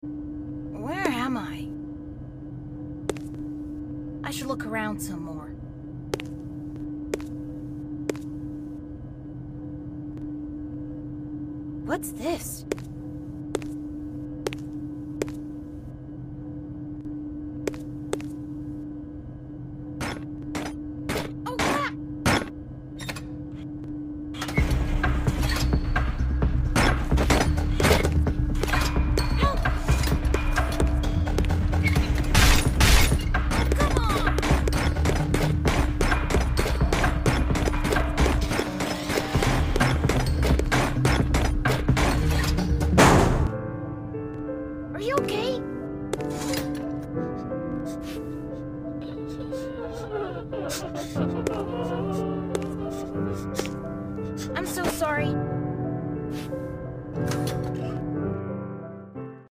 FNAF Movie Animatronics Counter Jumpscares sound effects free download
FNAF Movie Animatronics Counter Jumpscares (Chica & Gregory Voice Lines 🧑🐥)